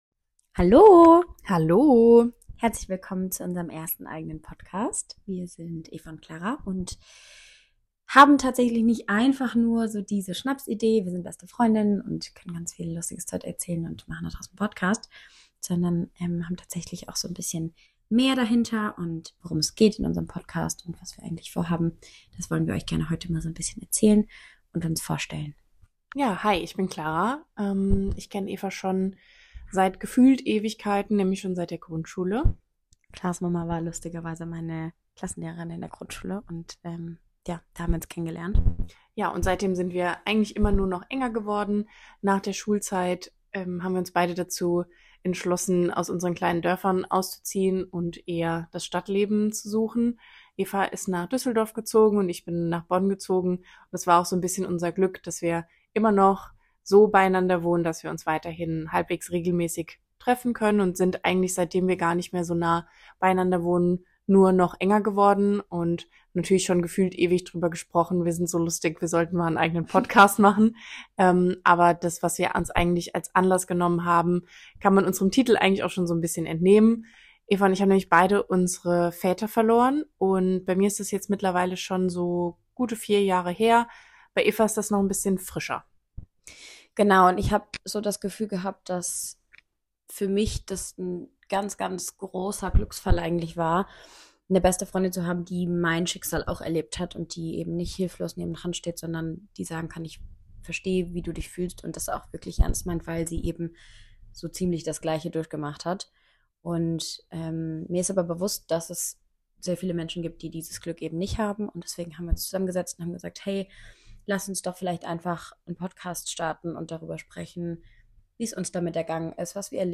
Darüber hinaus sind wir 2 Mittzwanziger Girls die zwischen
Und sind wir mal ehrlich - Laberpodcasts lieben wir doch